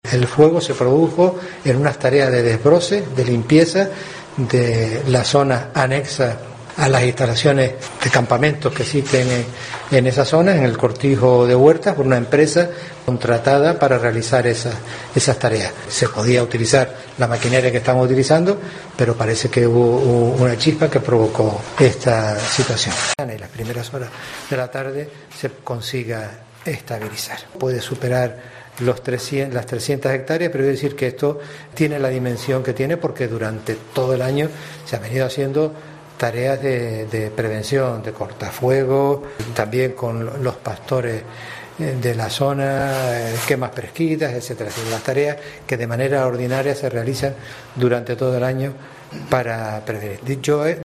Antonio Morales, presidente del Cabildo de Gran Canaria
Así lo ha anunciado en rueda de prensa el presidente del Cabildo de Gran Canaria, Antonio Morales, quien ha precisado que el fuego ha entrado en la Caldera, por lo que se trata de frenarlo en la ladera por la que discurre hasta su fondo, donde se prevé que muera.